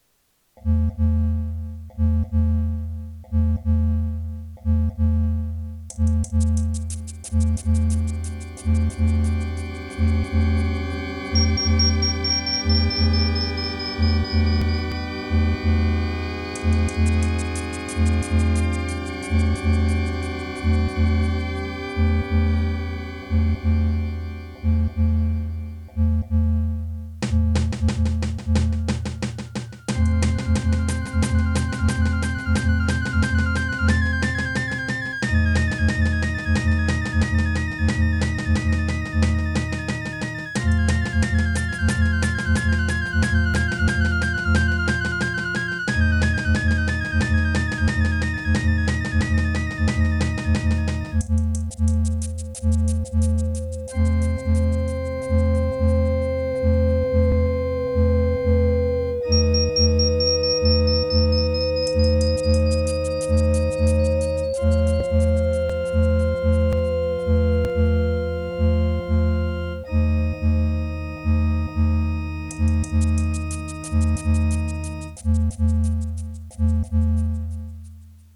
It is an unused music track.